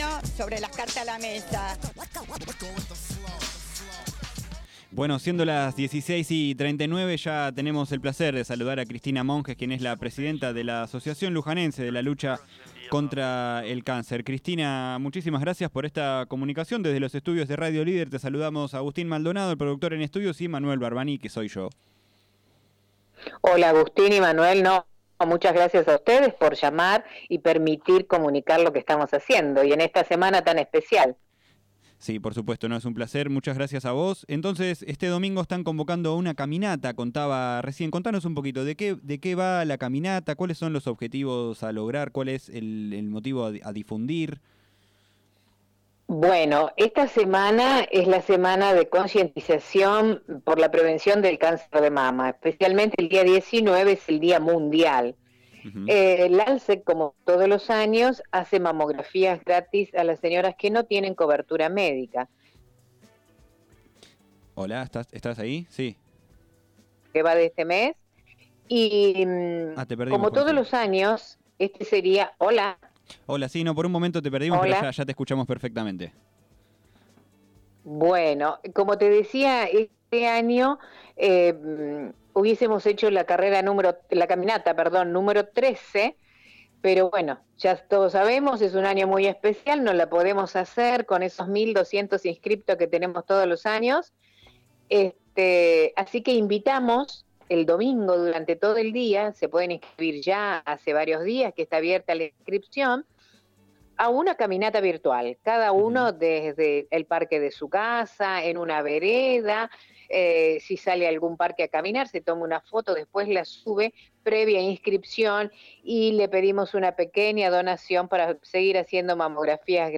durante el programa Sobre Las Cartas La Mesa de Radio Líder 97.7.